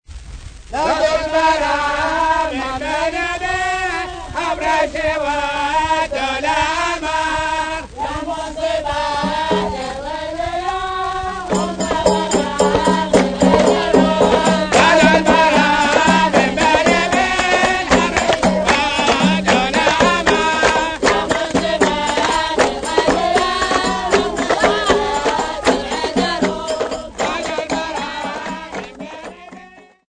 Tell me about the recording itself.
Field recordings Africa Kenya City not specified f-ke